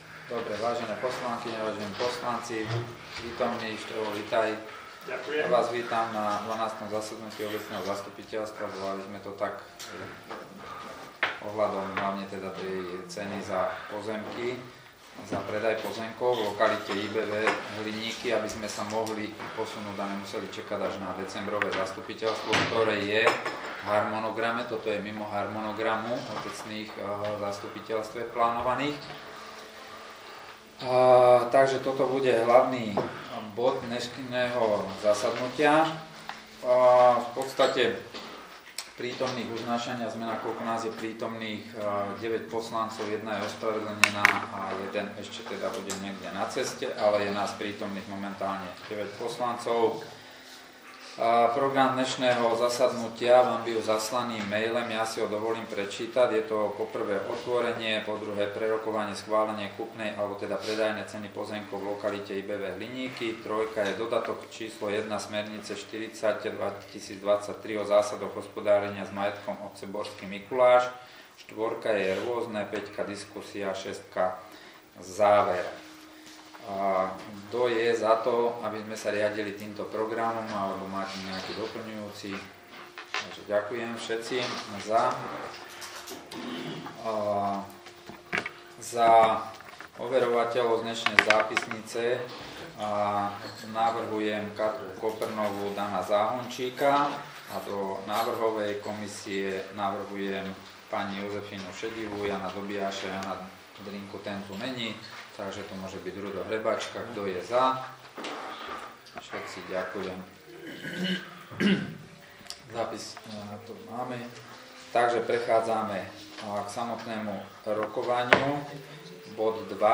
Borský Mikuláš - Portál elektronických služieb | Elektronické služby | Registre | Zvukový záznam z 12. zasadnutia OZ